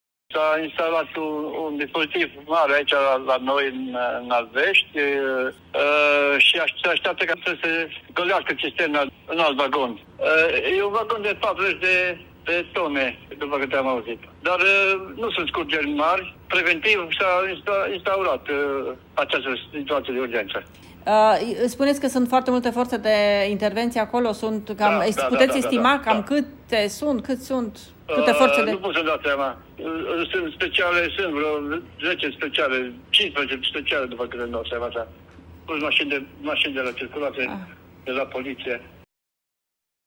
Carburantul va fi mutat în altă cisternă, spune primarul din Albești, Nicolae Șovrea: